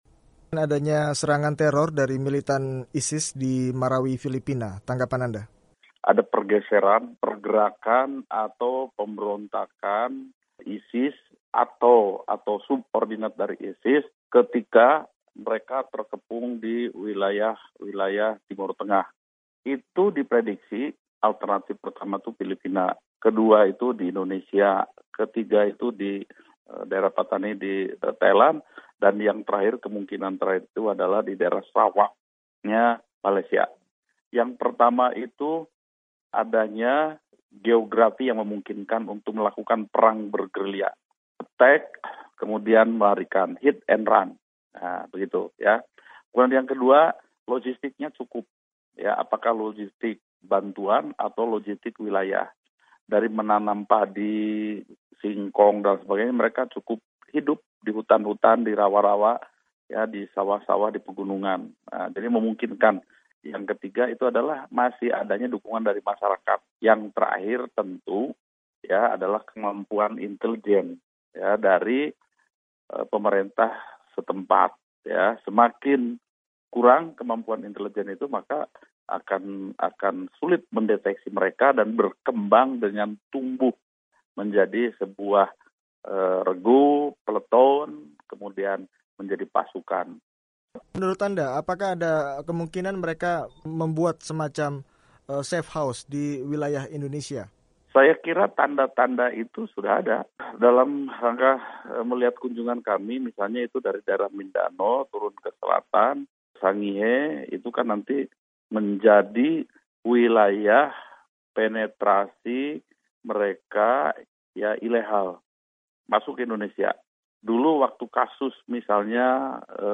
Wawancara TB Hasanuddin: Indonesia Waspadai Serangan ISIS